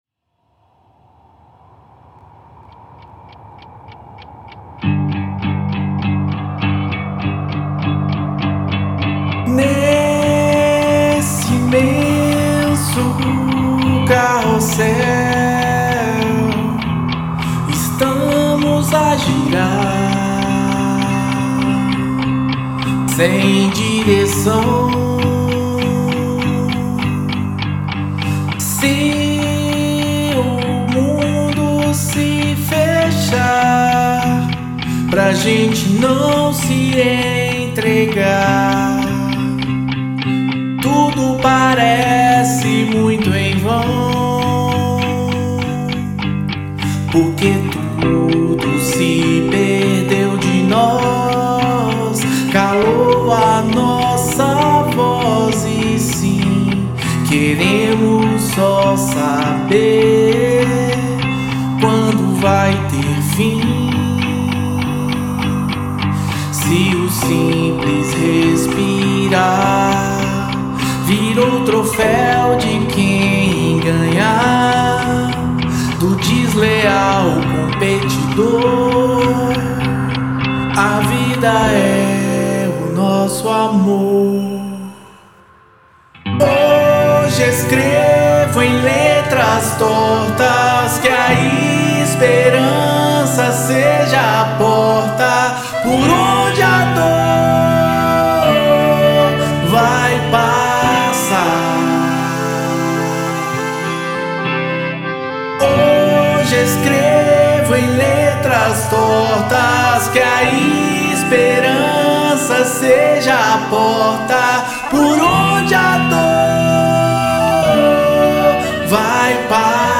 EstiloPop